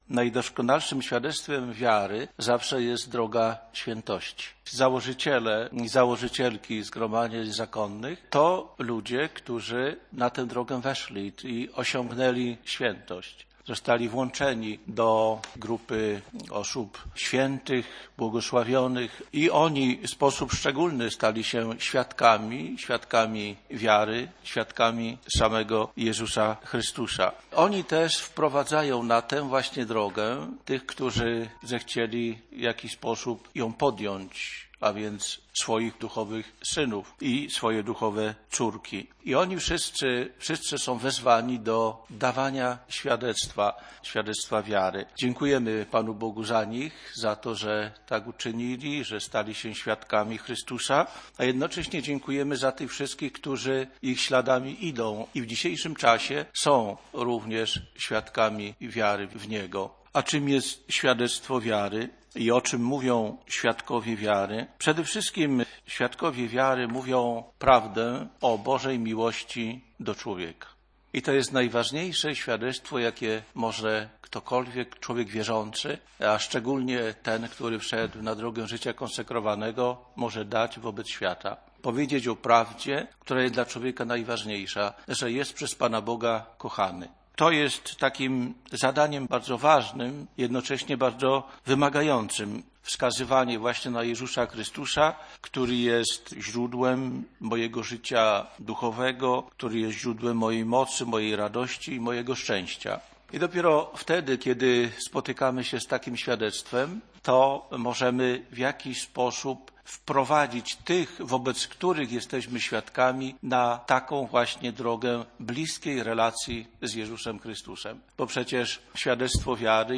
Z okazji zbliżającego się Światowego Dnia Życia Konsekrowanego odbyła się konferencja w siedzibie Konferencji Episkopatu Polski.